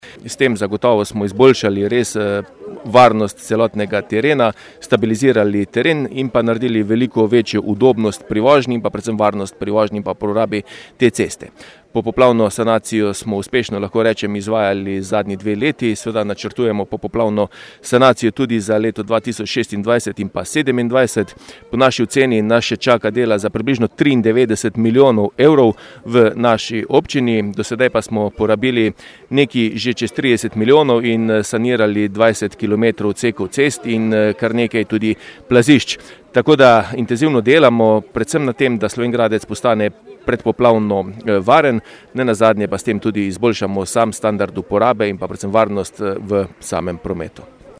Župan Tilen Klugler
IZJAVA KLUGLER 1_1.mp3